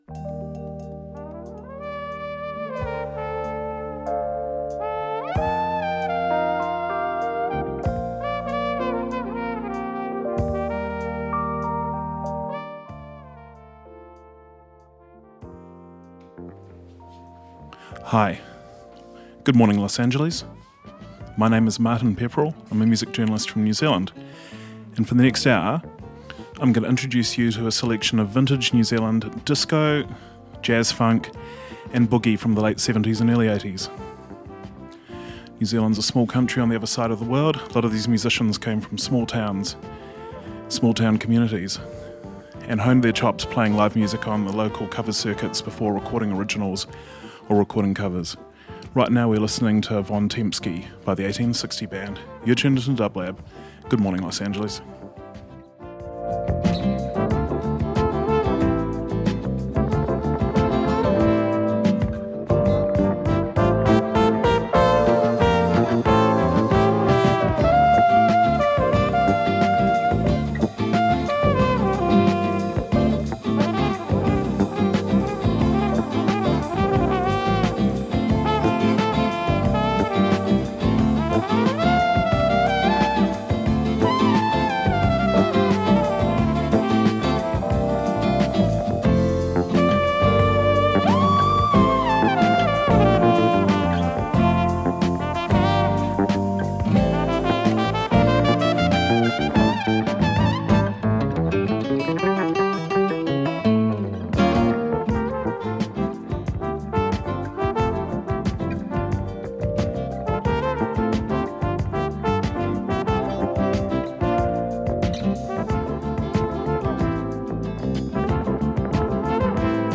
Disco/House Funk/Soul